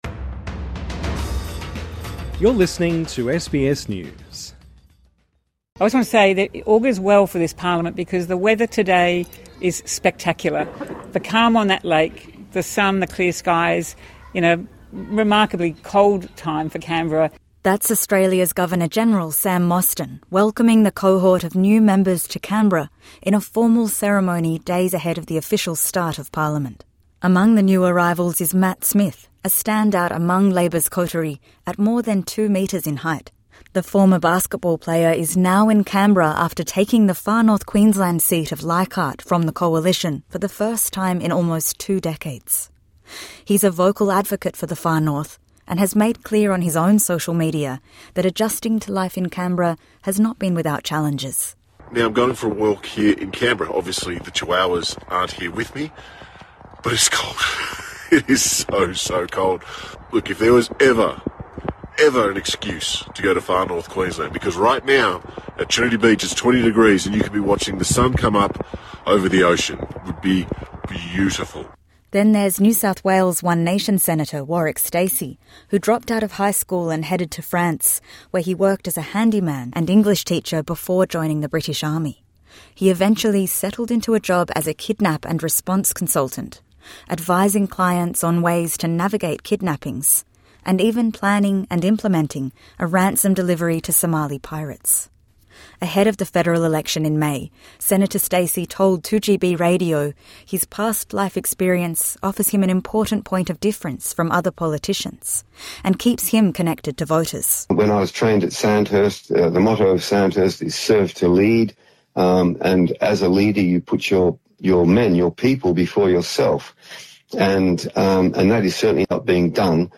INTERVIEW: What needs to be done about our gun laws?. Are Australia's gun laws tough enough?